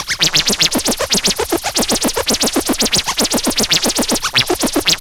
MOOG_SCRATCHES_0003.wav